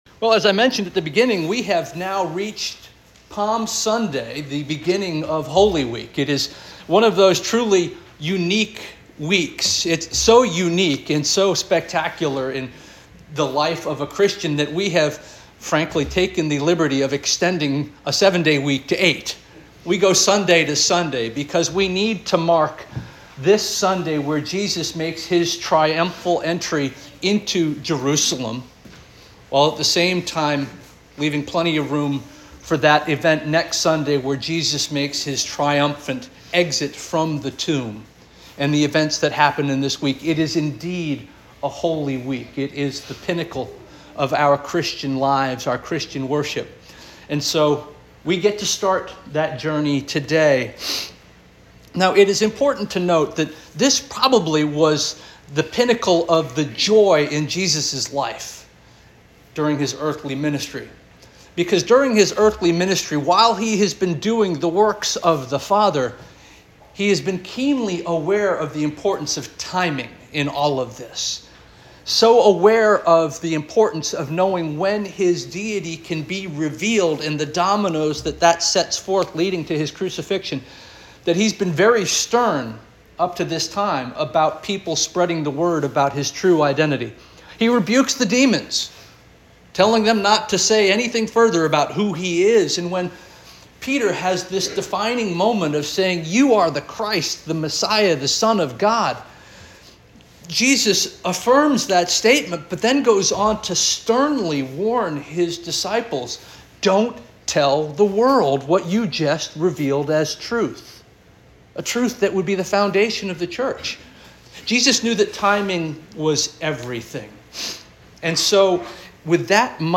April 13 2025 Sermon - First Union African Baptist Church